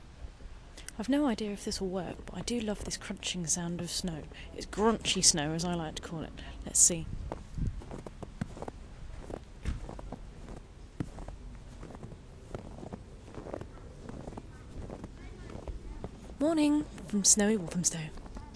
"Grraunchhy" snow.
89000-grraunchhy-snow-good-morning.mp3